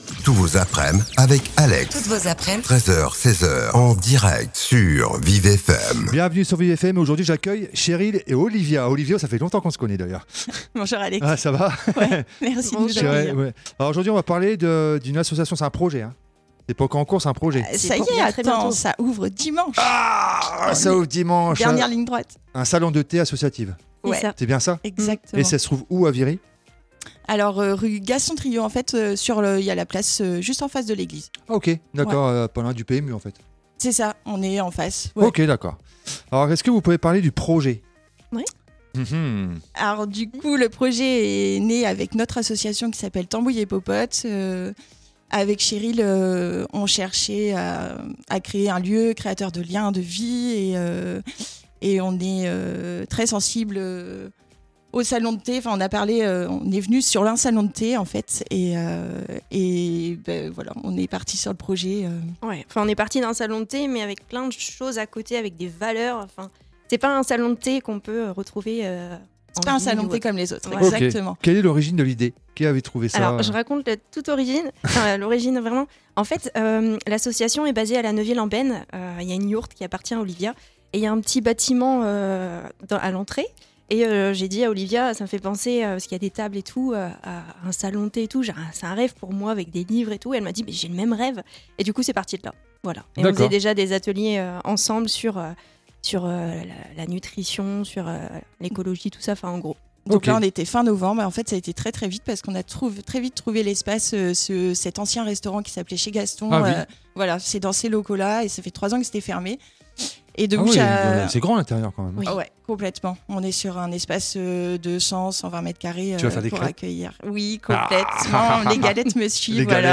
Interview Thé associatif Viry.
Interview-The-associatif-Viry.mp3